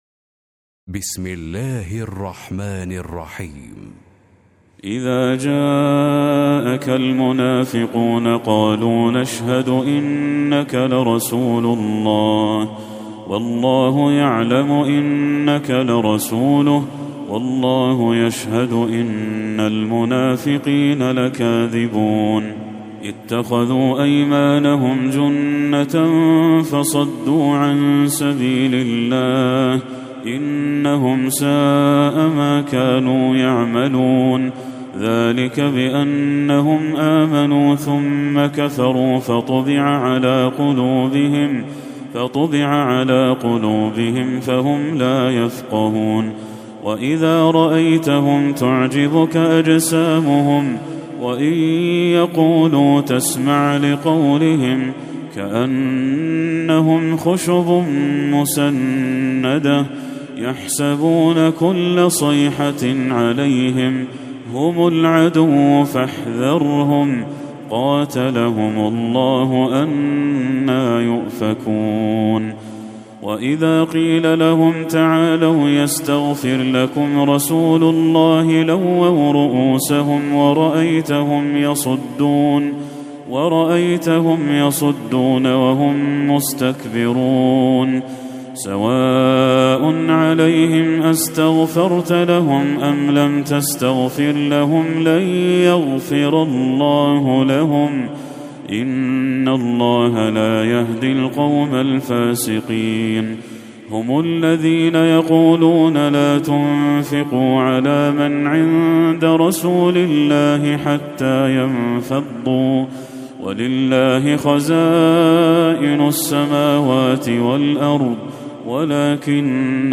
سورة المنافقون Surat Al-Munafiqoon > المصحف المرتل